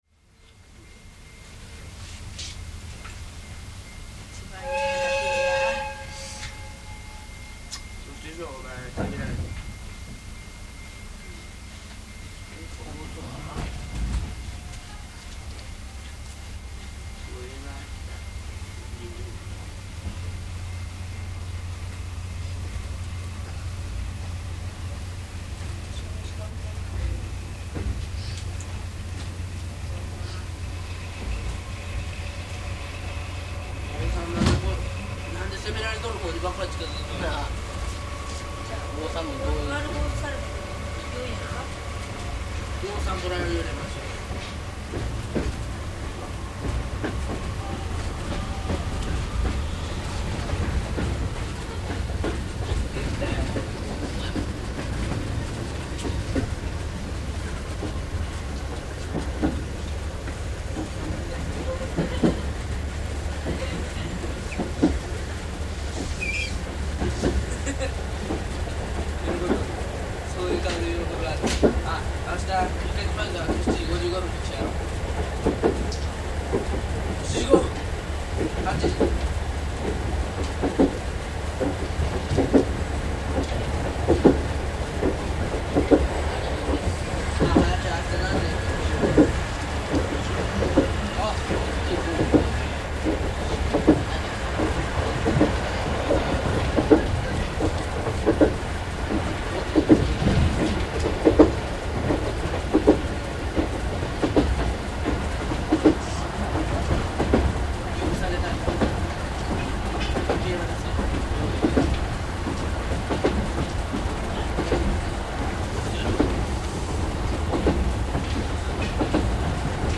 上と同じ列車。列車は西舞鶴へ向かって快走。しばらくすると車内放送で宮津線への乗り換え案内。西舞鶴構内に入るとゆっくりとホームに
滑り込みます。将棋は勝負がつかず来週に持ち越し。停車間際に、「よく止まるまでお待ちください」と客車列車独特の構内アナウンス。
乗客がほぼ全部入れ換わる西舞鶴駅。乗客の乗り降りの足音や話し声が活気を感じさせます。　　昭和56（1981）年2月録音